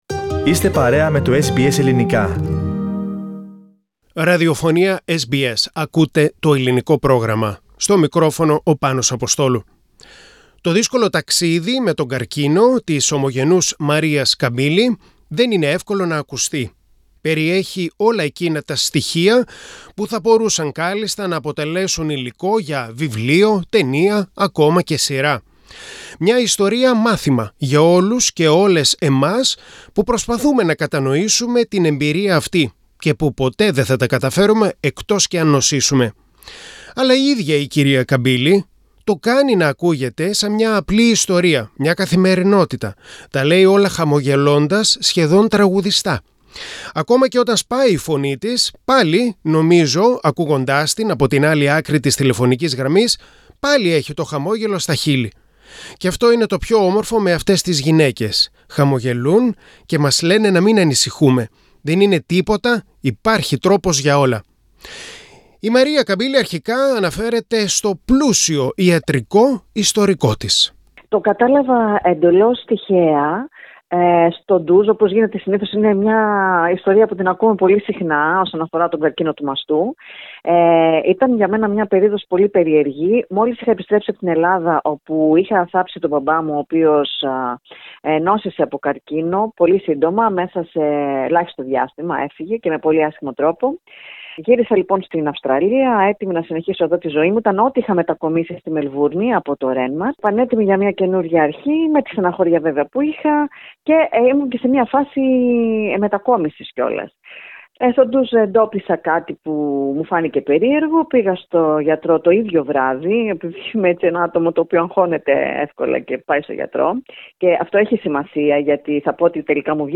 τα λέει όλα χαμογελώντας, σχεδόν τραγουδιστά. Ακόμα και όταν «σπάει» η φωνή της, πάλι, ακούγοντάς την από την άλλη άκρη της τηλεφωνικής γραμμής, πάλι, έχει το χαμόγελο στα χείλη.